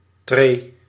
Ääntäminen
Vaihtoehtoiset kirjoitusmuodot trede Synonyymit treeplank trede opstap opstapje Ääntäminen Tuntematon aksentti: IPA: [treː] Haettu sana löytyi näillä lähdekielillä: hollanti Käännös 1. degrau {m} Suku: m .